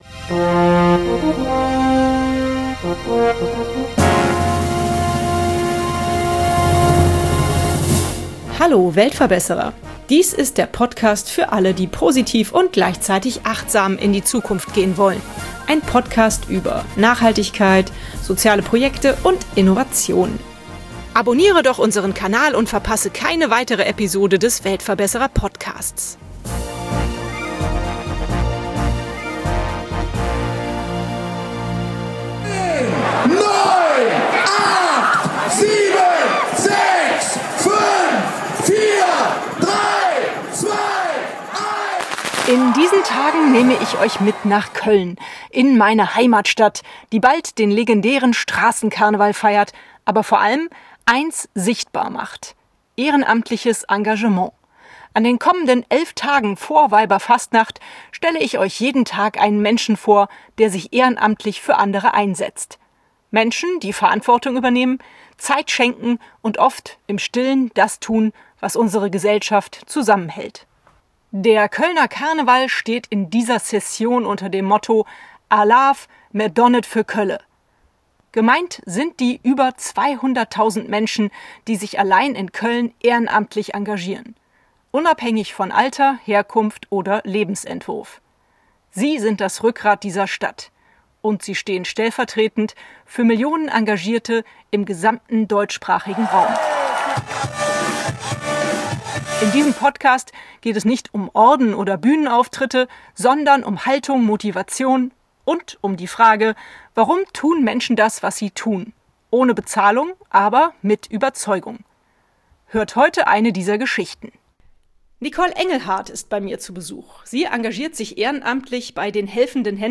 In diesem Podcast geht es nicht um Orden oder Bühnenauftritte, sondern um Haltung, Motivation und die Frage: Warum tun Menschen das, was sie tun – ohne Bezahlung, aber mit Überzeugung? Heute hört ihr eine dieser Geschichten...